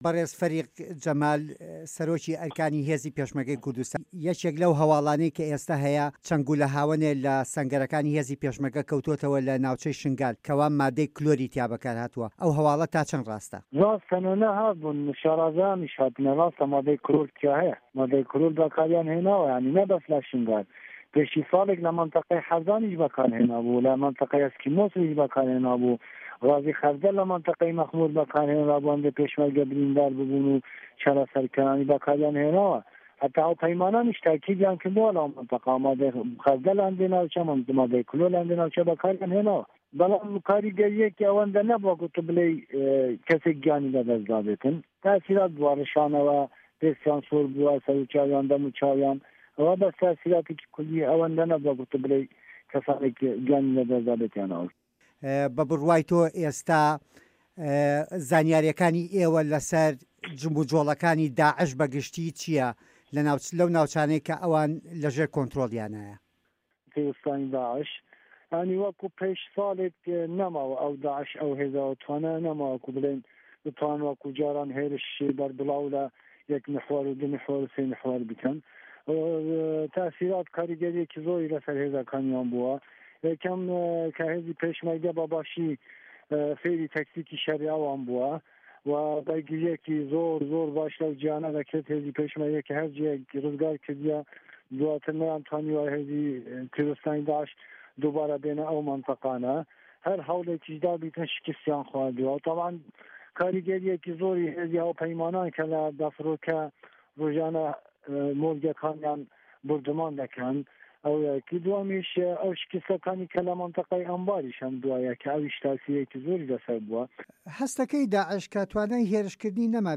وتووێژ لەگەڵ فەریق جەمال محەمەد عومەر